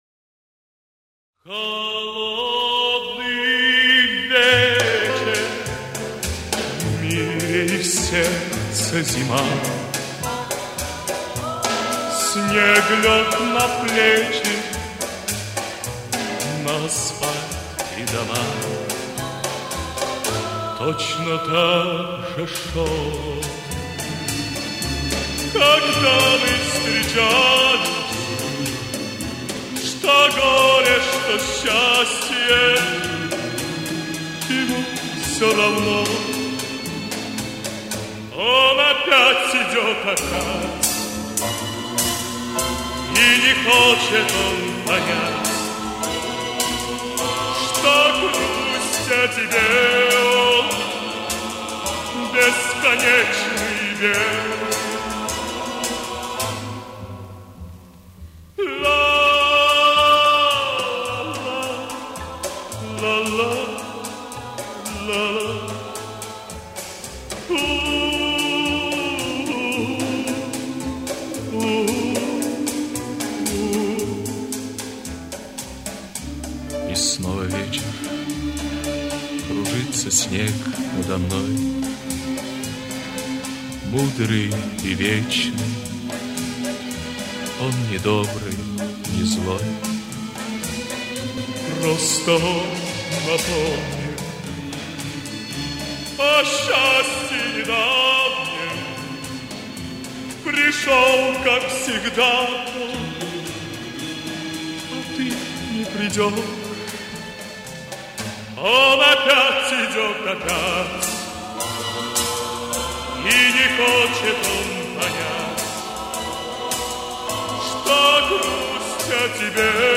А этот инструментальный вариант мне нравится больше всего!